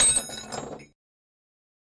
金属凿子击球 " 凿子击球04
描述：金属凿子互相落下。
Tag: 下降 掉落 凿子 金属 工业